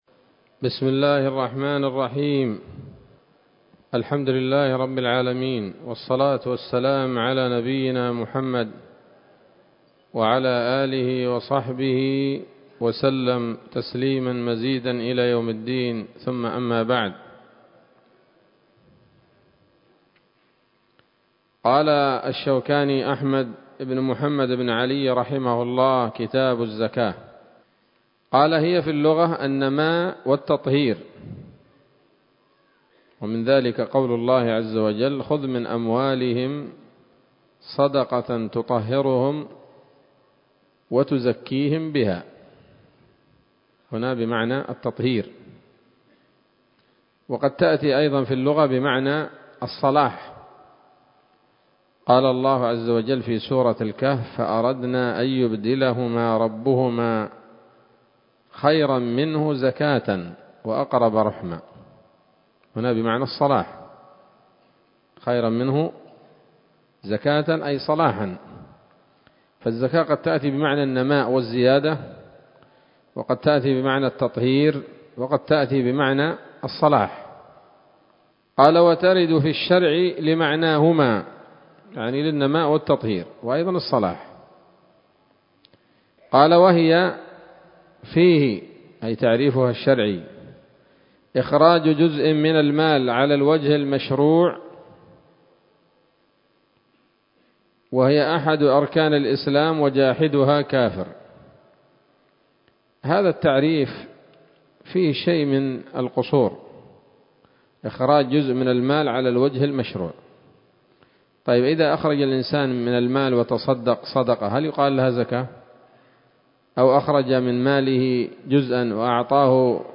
الدرس الأول من كتاب الزكاة من السموط الذهبية الحاوية للدرر البهية